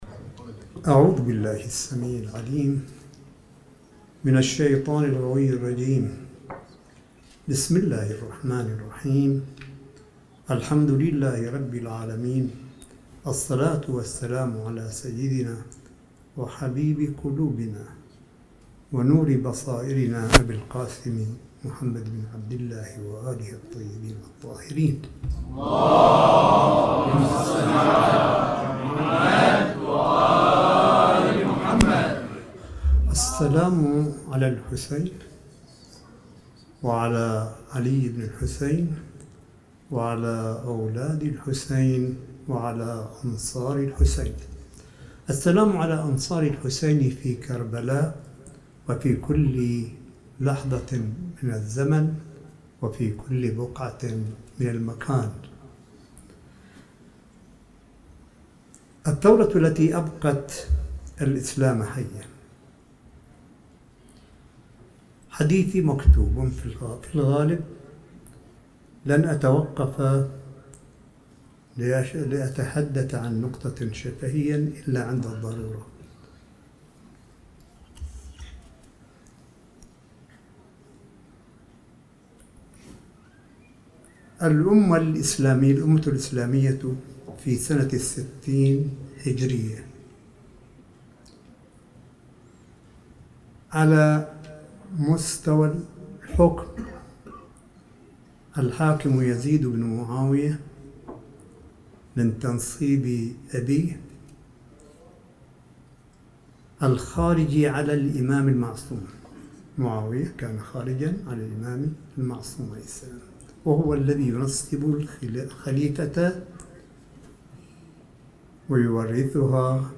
ملف صوتي لكلمة سماحة آية الله الشيخ عيسى أحمد قاسم في موسم عاشوراء ١٤٤١هـ الجزء الأوَّل مِن عنوان (الثورة التي أبقت الإسلام حيًّا) في الحسينية البحرانيّة بـ قم المقدسة – ٢٩ أغسطس ٢٠١٩م